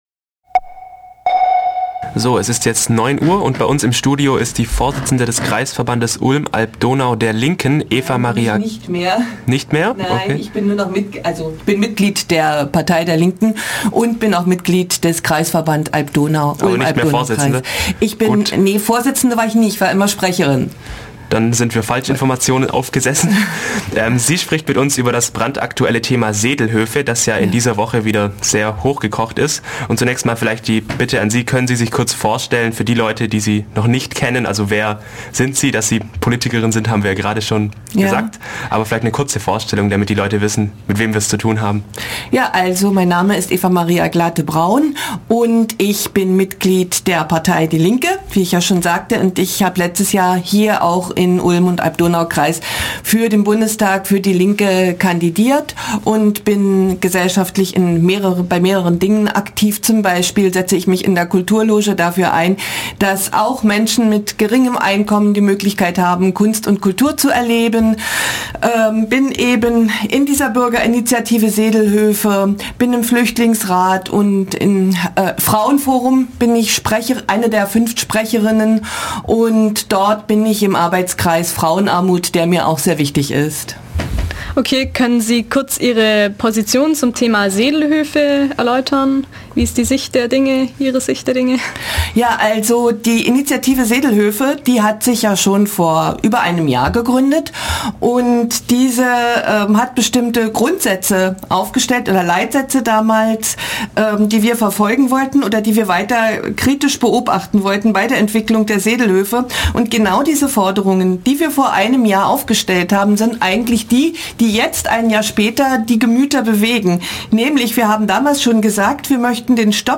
Interview mit Eva-Maria Glathe-Braun
Heute bei uns zu Gast war die Politikerin Eva-Maria Glathe-Braun von der Linken, die mit uns über die Zukunft des Projekts Sedelhöfe gesprochen hat.
interview_sedelhoefe.mp3